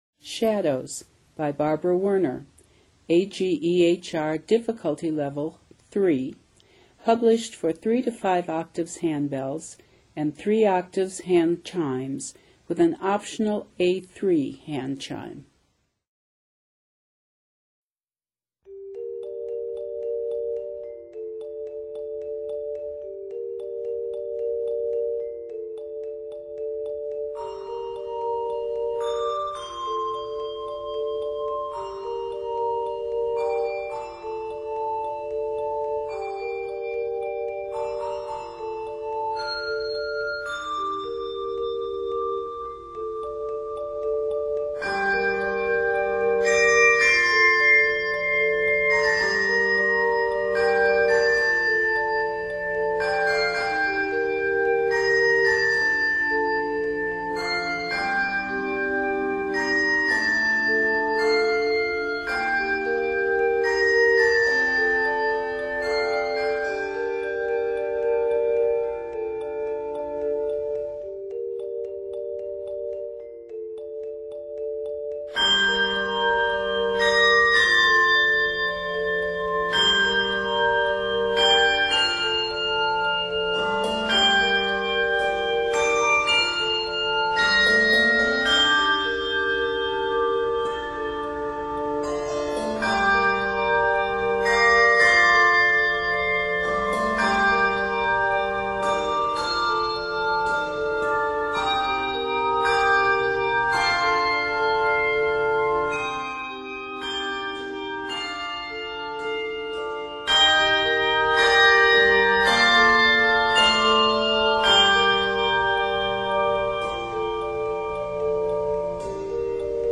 Arranged in g minor, this piece is 88 measures.
N/A Octaves: 3-5 Level